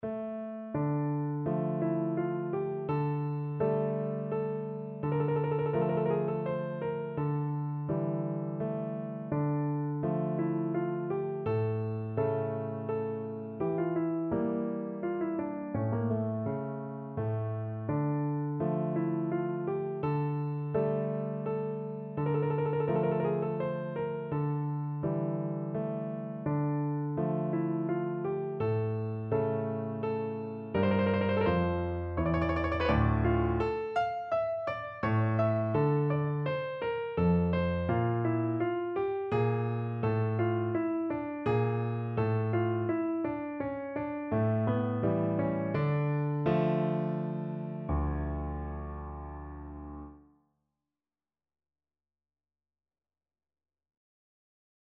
Piano version
No parts available for this pieces as it is for solo piano.
3/4 (View more 3/4 Music)
Andante =84
Piano  (View more Easy Piano Music)
Classical (View more Classical Piano Music)